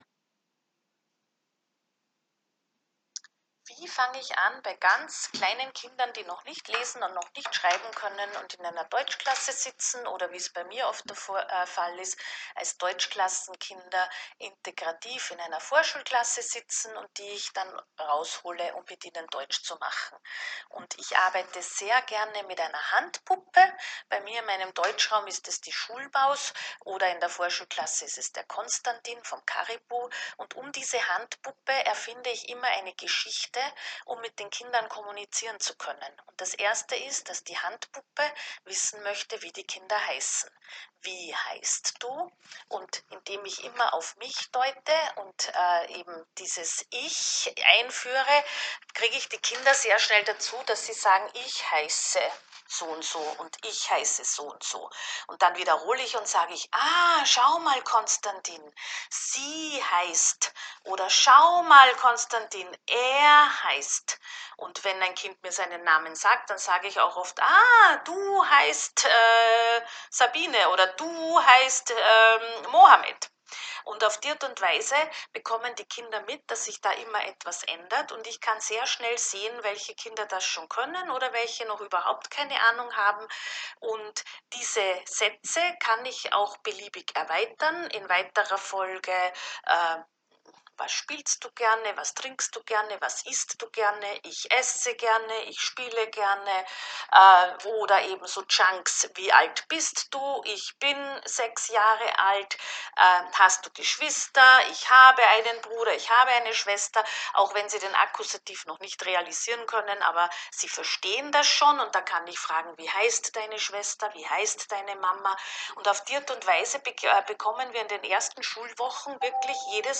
Erfahrungsberichte In folgenden Audios erzählen je zwei Lehrerinnen der Primar- und Sekundarstufe I von ihren Erfahrungen in der Ankommensphase des DaZ-Anfangsunterrichts.